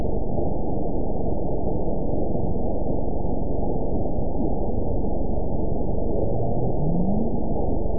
event 913935 date 04/24/22 time 00:01:41 GMT (3 years ago) score 9.38 location TSS-AB05 detected by nrw target species NRW annotations +NRW Spectrogram: Frequency (kHz) vs. Time (s) audio not available .wav